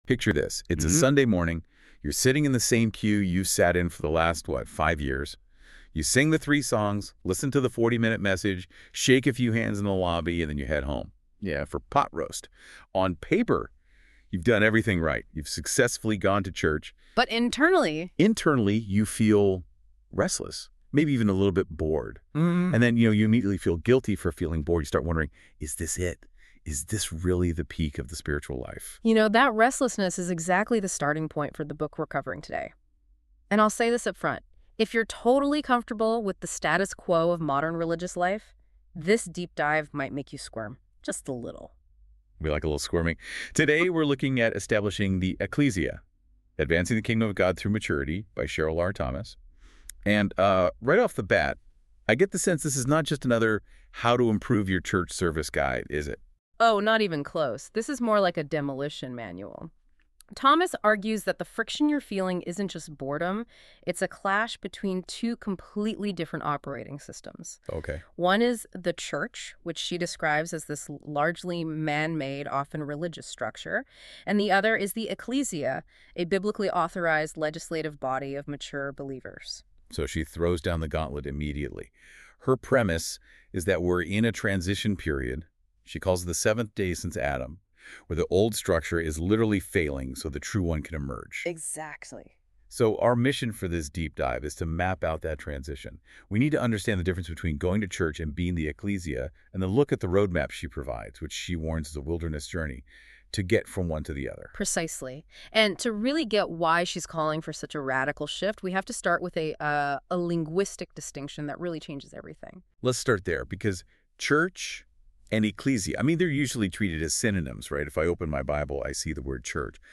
Establishing-the-Ekklesia-podcast-ai-generated.mp3